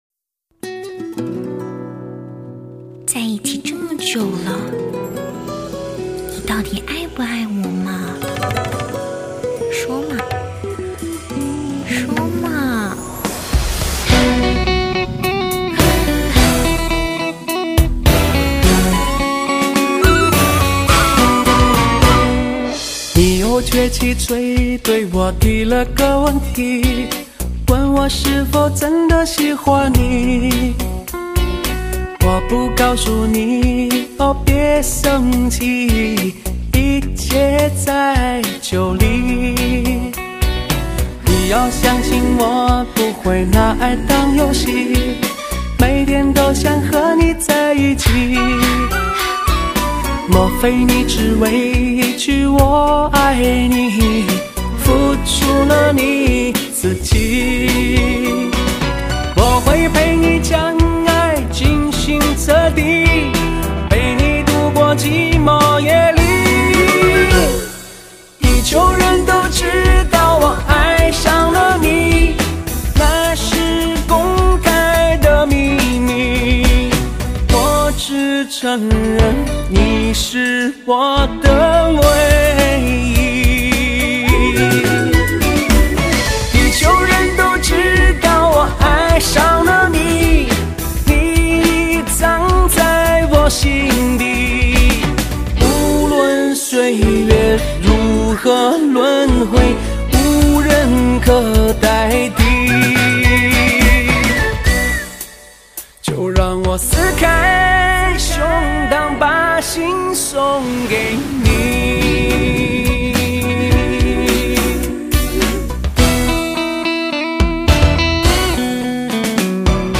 真情的诉说，最畅销流行热曲全纪录，伤心绝唱，精选好歌，伤感至极，聆听音乐感受音乐……